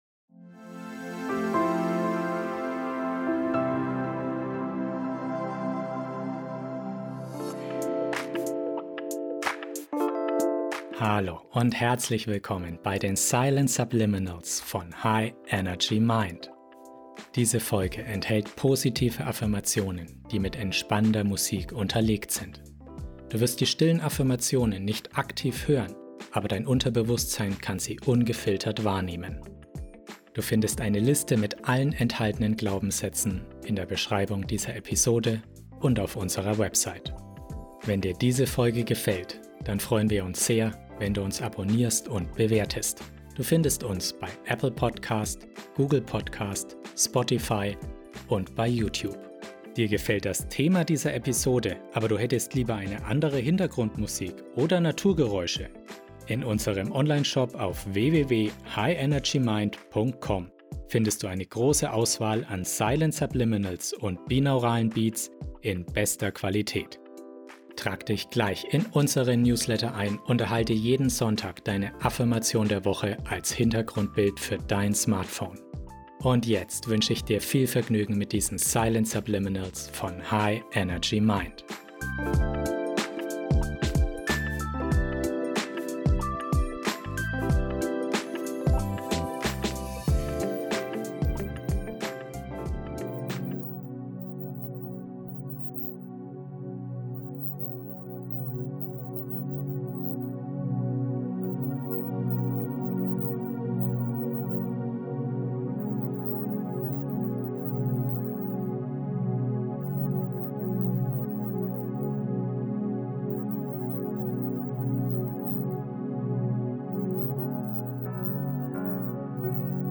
Über diese Folge In dieser inspirierenden Folge im Silent Subliminals Podcast erfährst du, wie du Vertrauen in die Zukunft aufbauen kannst. Die beruhigende Musik in 432 Hz begleitet kraftvolle Silent Subliminals, die dein Unterbewusstsein erreichen und dein Vertrauen in die kommenden Tage stärken.
Silent Subliminals sind speziell modulierte Audiodateien, die Botschaften auf einer Frequenz senden, die bewusst nicht hörbar ist, aber vom Unterbewusstsein wahrgenommen wird.